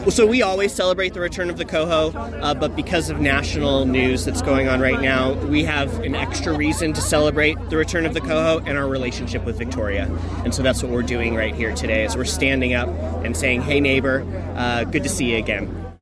A welcoming event was planned by the Port Angeles Waterfront District, and about 150 people came down to show their support for Canada and the long and prosperous relationship between the cities of Port Angeles and Victoria.